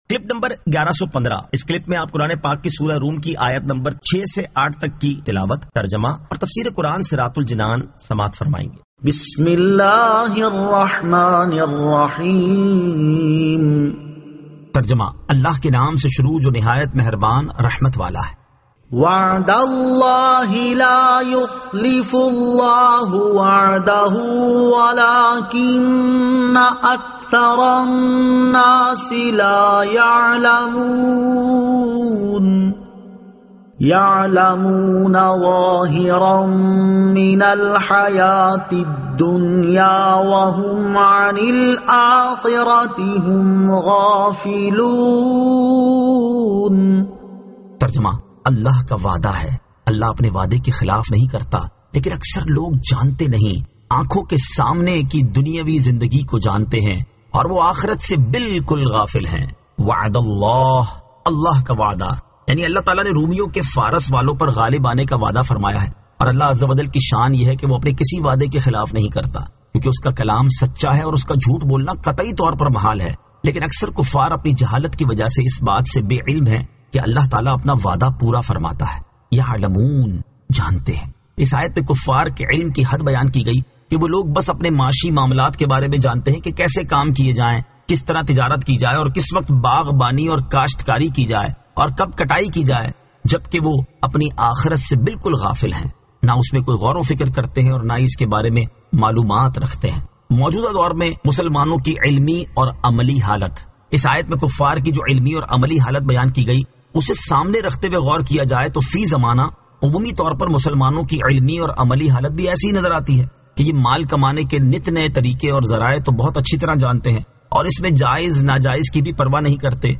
Surah Ar-Rum 06 To 08 Tilawat , Tarjama , Tafseer